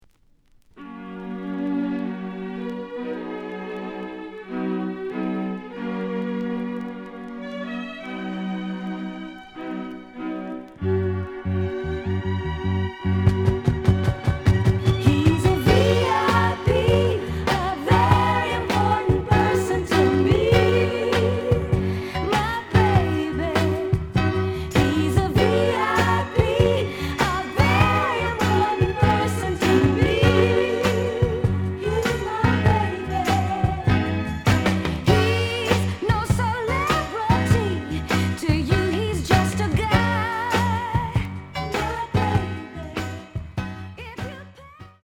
The audio sample is recorded from the actual item.
●Genre: Soul, 70's Soul
B side plays good.)